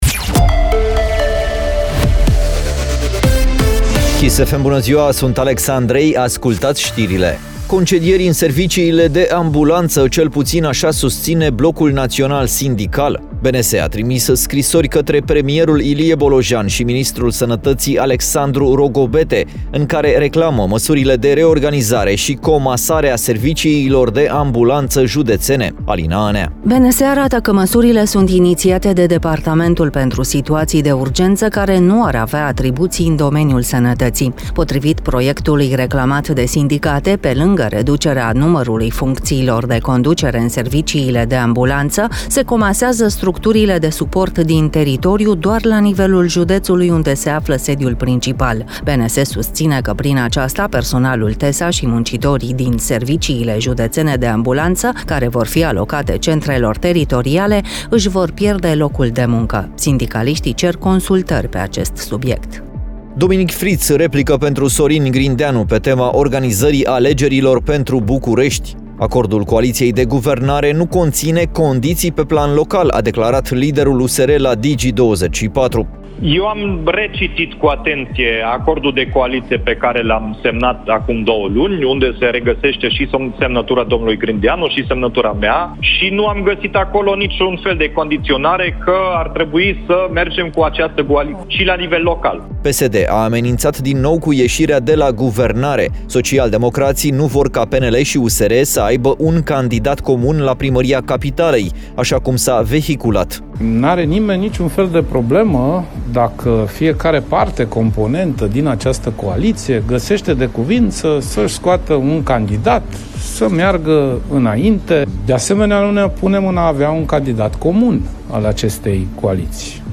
Știrile zilei de la Kiss FM